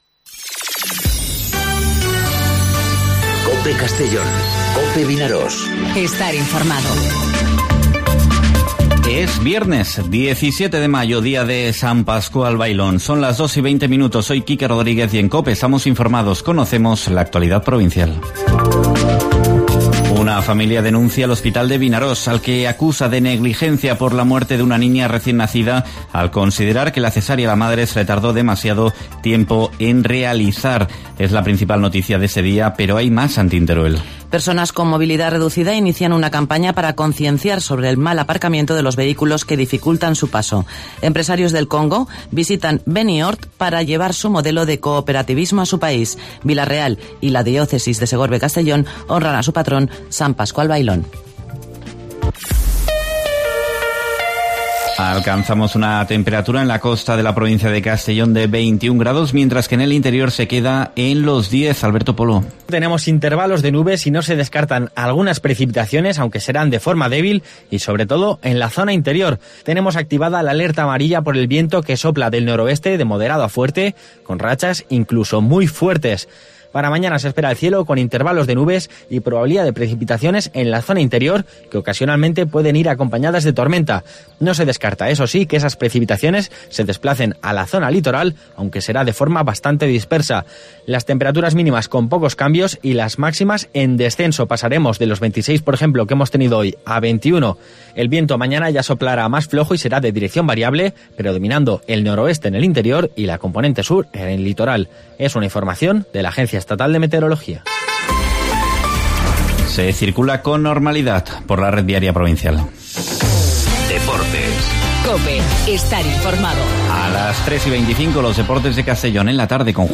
Informativo 'Mediodía COPE' en Castellón (17/05/2019)